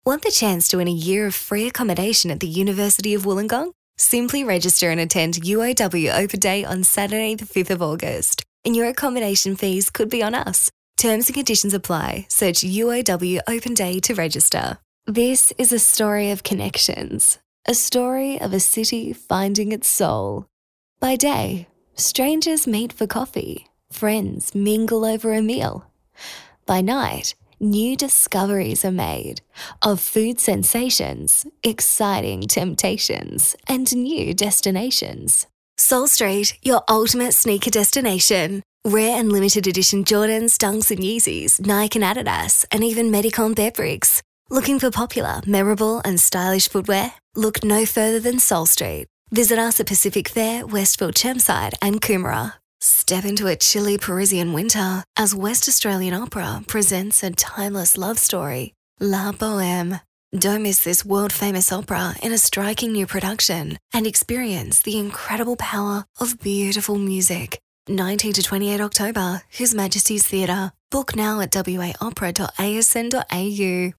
• Soft Sell
• Young
• Bright
• Fresh & Friendly
• Neumann TLM 103
• Own Home Studio